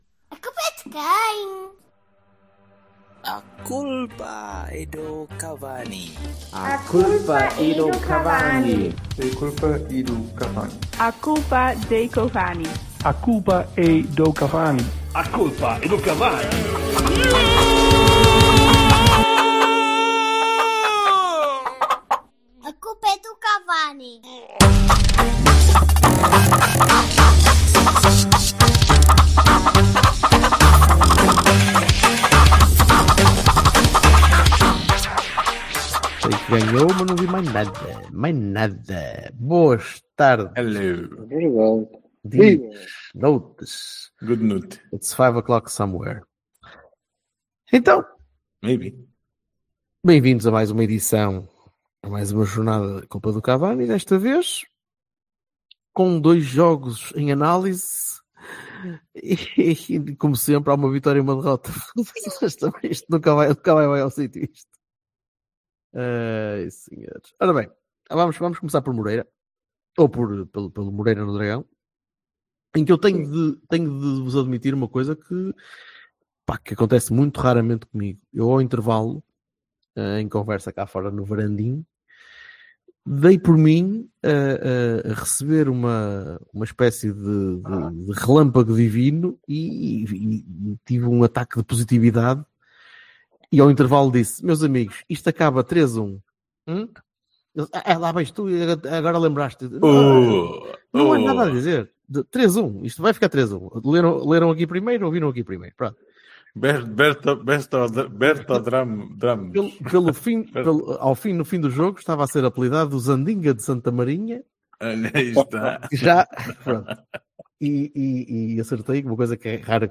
Três portistas.
Um microfone reles.